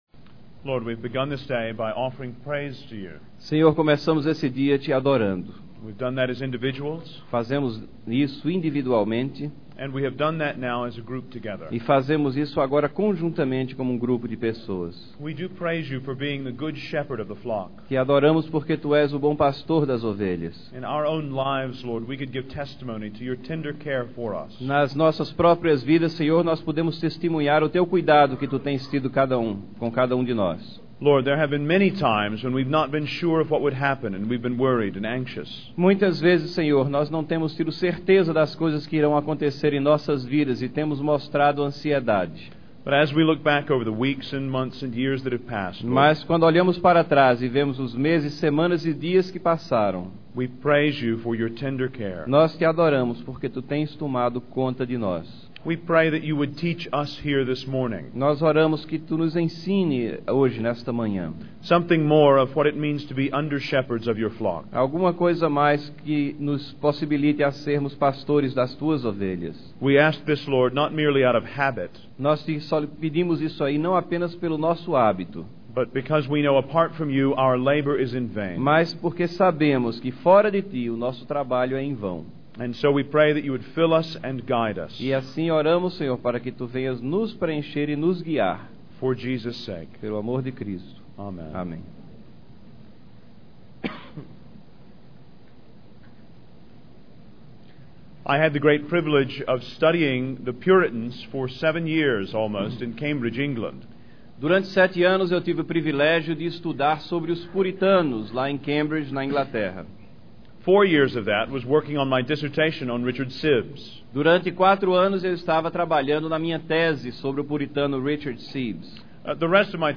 12ª Conferência Fiel para Pastores e Líderes – Brasil - Ministério Fiel